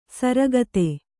♪ saragatge